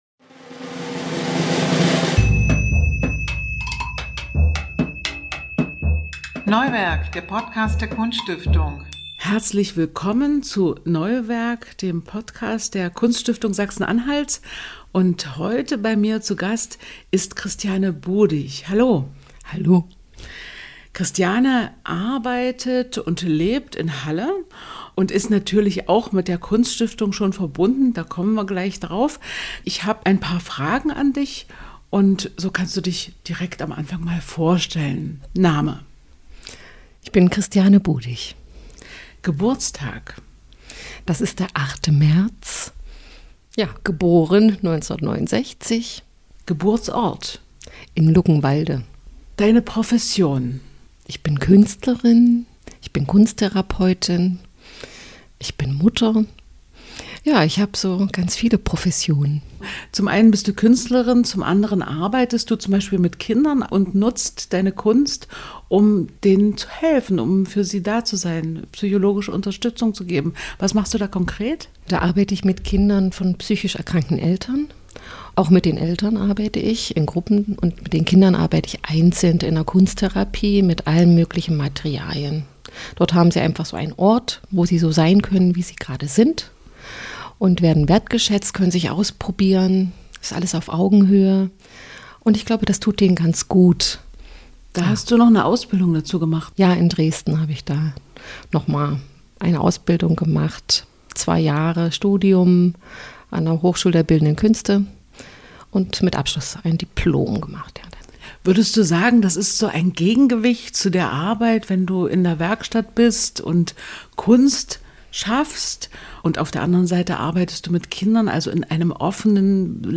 Gespräche über Kunst und die Welt - im Podcast der Kunststiftung